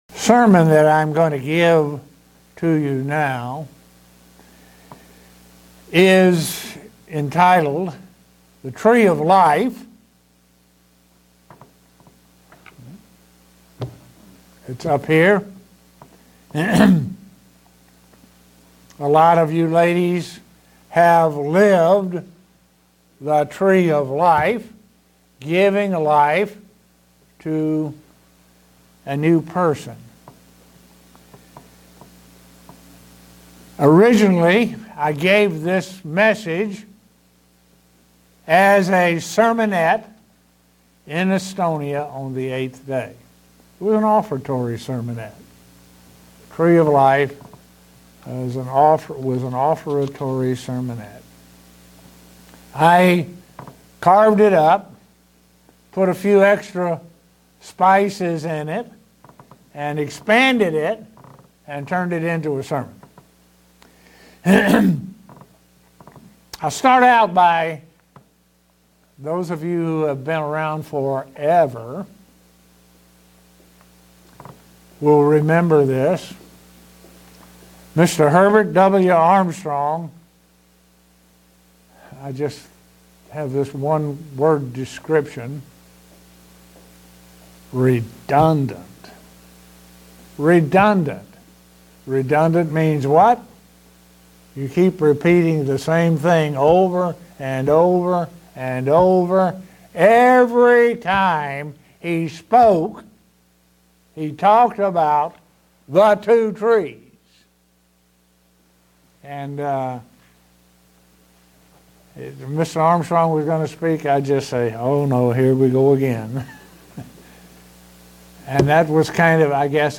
Given in Buffalo, NY
Print How Eternal Life was lost by humanity and the method used to return it. sermon Studying the bible?